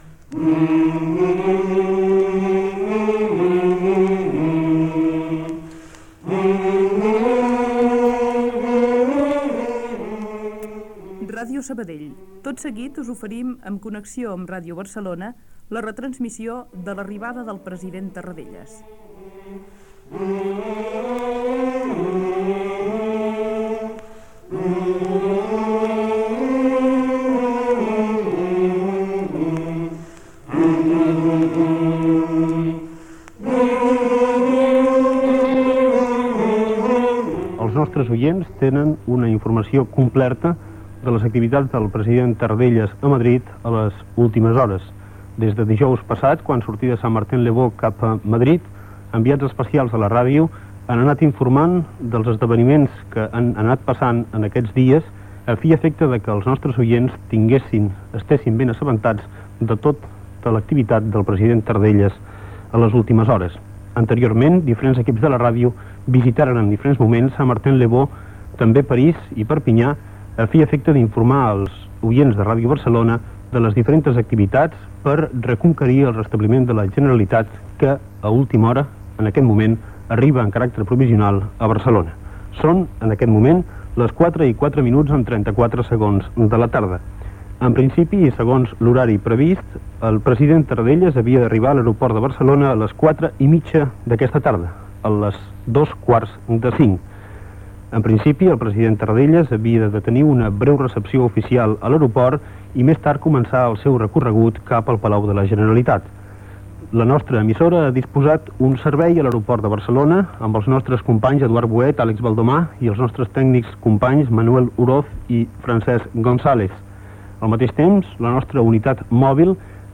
Connexió amb Ràdio Barcelona per oferir el programa especial dedicat al retorn a Catalunya del president de la Generalitat Josep Tarradellas
Informatiu